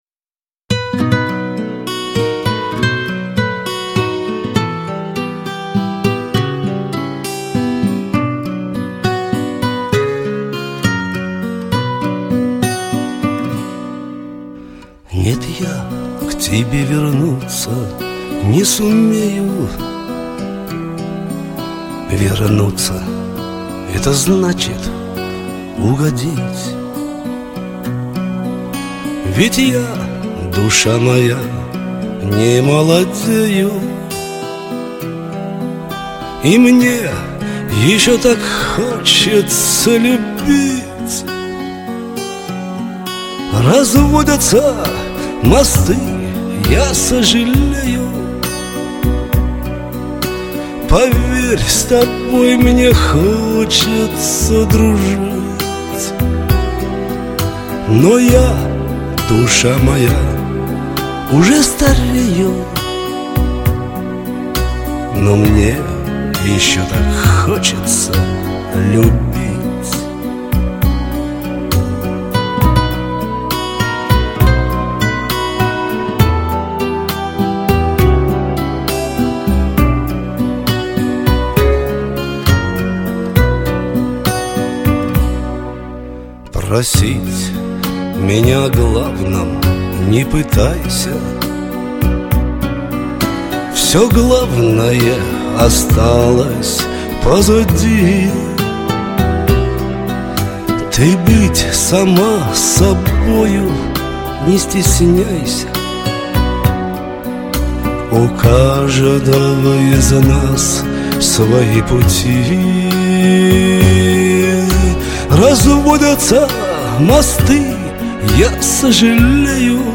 И очень лиричная!